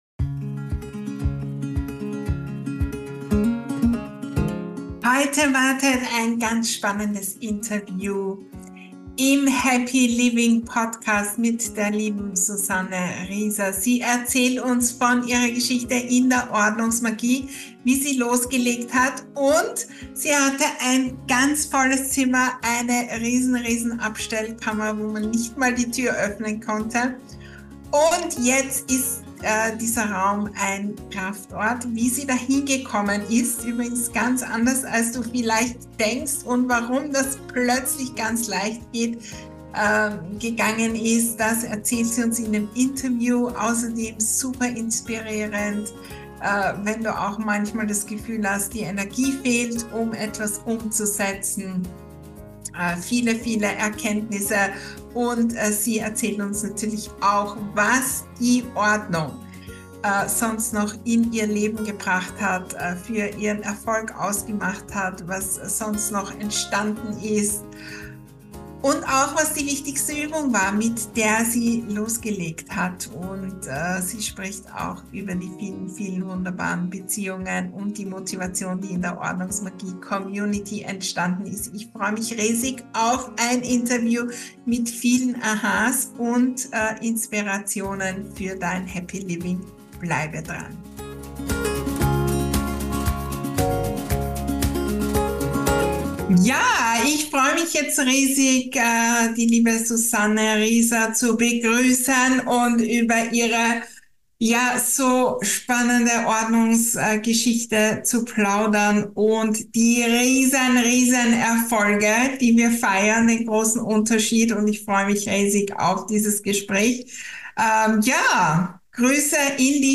Bevor sie mit der OrdnungsMagie losgelegt hat, hatte sie einen ganz vollen Raum, dessen Tür man kaum öffnen konnte. Im Interview erzählt sie, wie sie diesen Raum zum Kraftort gestaltet hat und warum das plötzlich ganz leicht gegangen ist. Freue dich auf ein Interview mit vielen Erkenntnissen und Inspirationen für dein Happy Living.